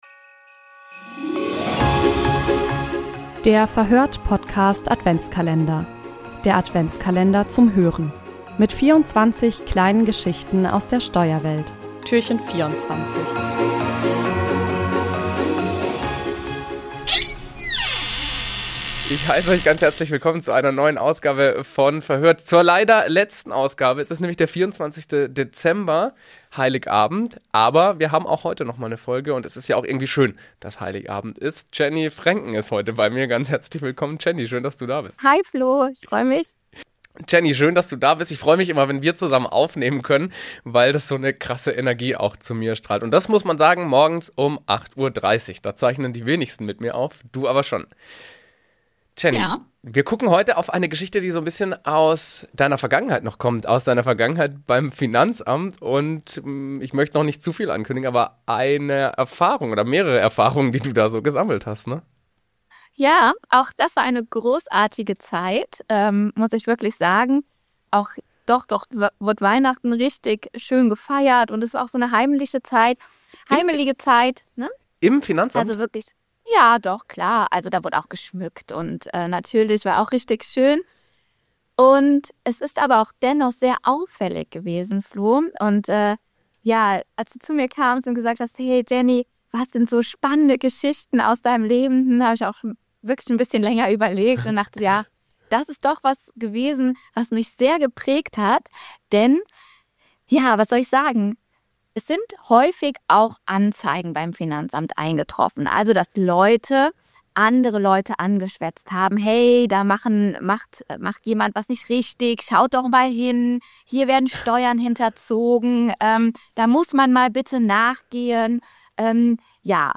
Im Verhör(t) Podcast-Adventskalender erzählen Steuerexpertinnen und Steuerexperten Geschichten und Anekdoten aus ihrem Alltag.